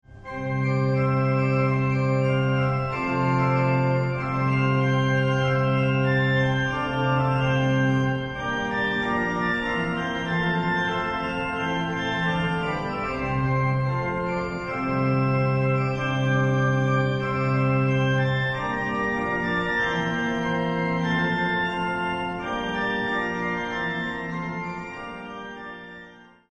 eseguite all'organo a canne
Organo costruito dai Fratelli Collino nel 1887 a Torino